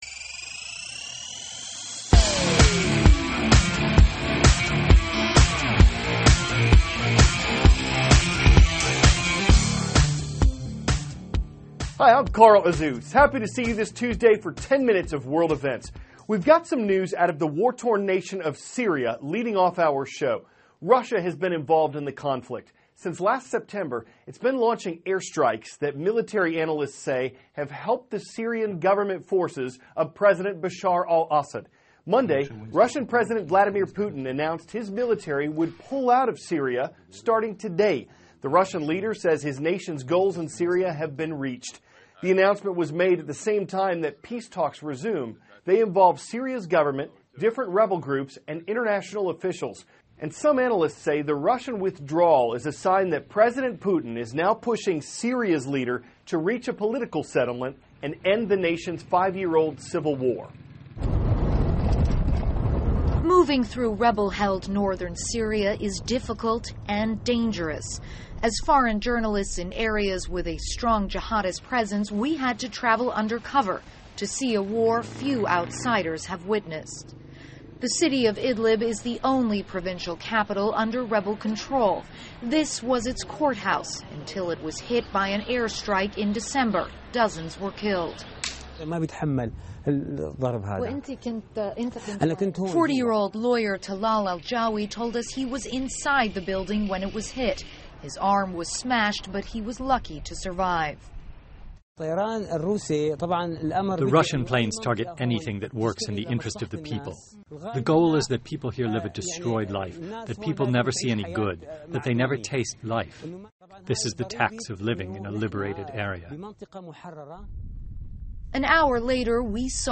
(CNN Student News) -- March 15, 2016 Russia to Withdraw Forces from Syria; U.S. Election: Super Tuesday 3; Early Pollen Season. Aired 4-4:10a ET THIS IS A RUSH TRANSCRIPT.